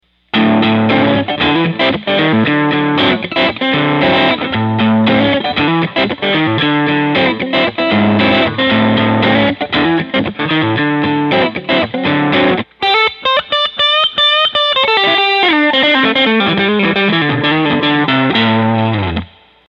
I used my loop station to record a 20 second guitar riff used it to record 5 different amp settings before I modded the amp.
I cranked the volume, which saturates the preamp stage with gain, and left the treble, bass and middle knows at 12.
The distortion in the “before” example has a clear articulation to it, while improved bass in the “after” example simply muddies the sound up.